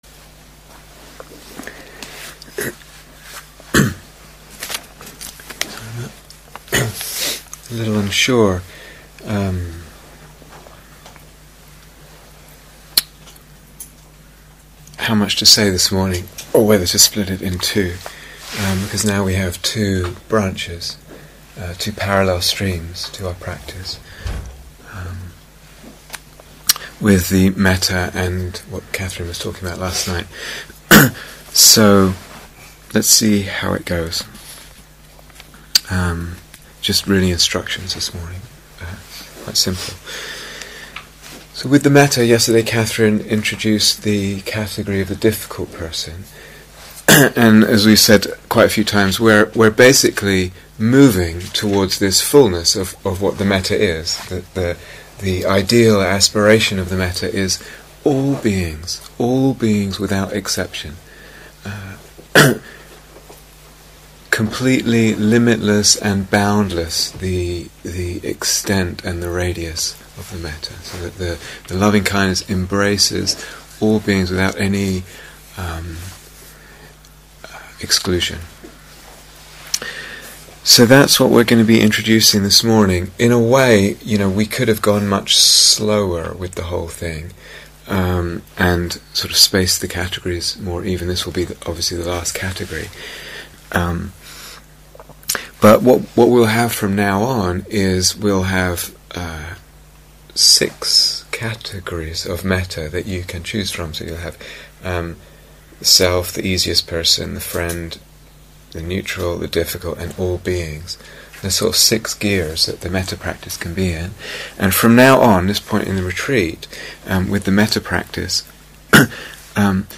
Seventh Instructions and Guided Mettā Meditation: Mettā and Emptiness